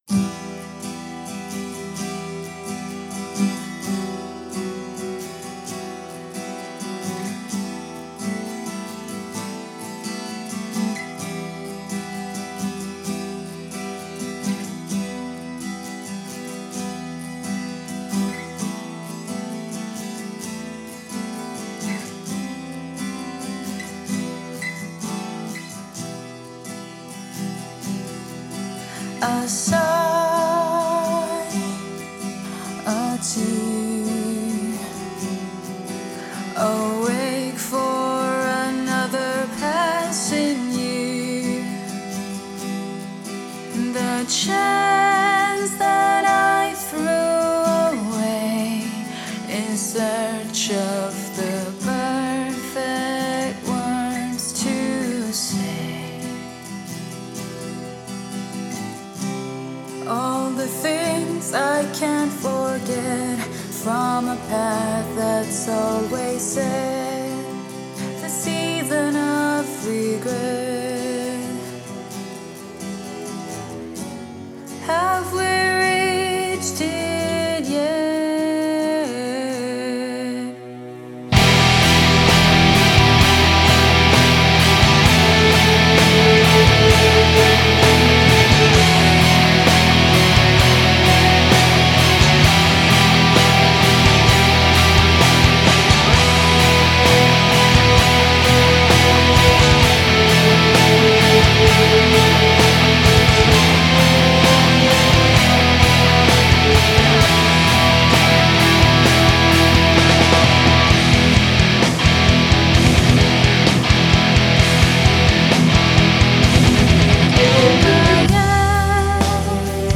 Альтернативная музыка Gothic Metal